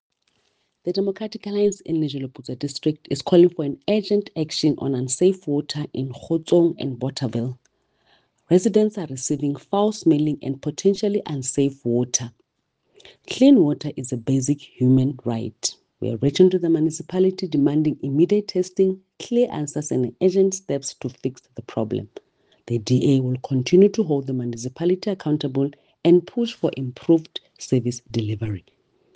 Sesotho soundbites by Cllr Mahalia Kose and